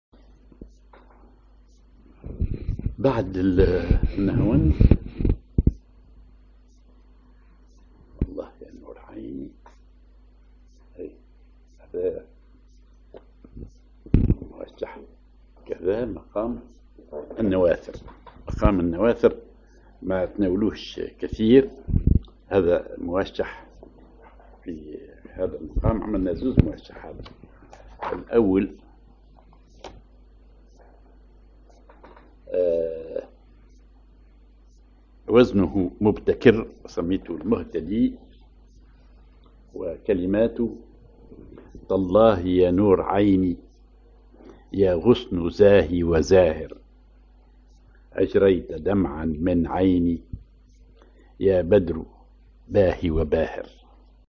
ar نواثر
موشح